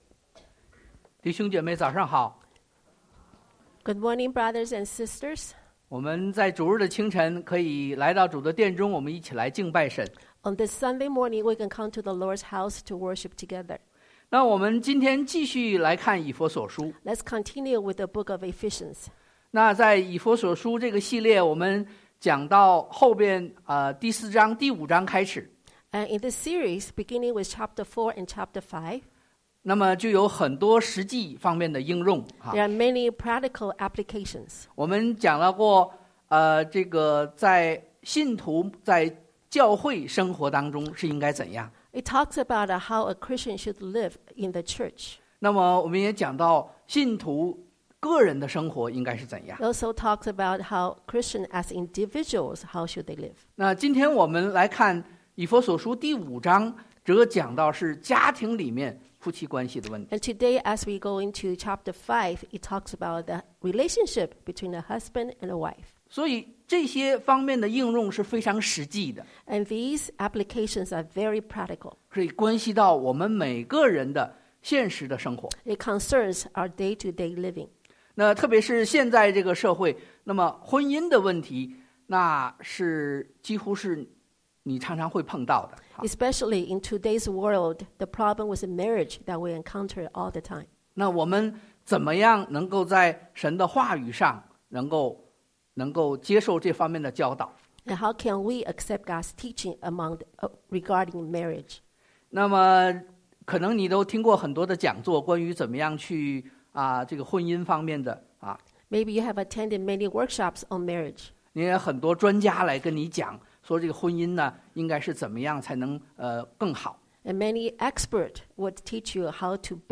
Eph 5:22-32 Service Type: Sunday AM Bible Text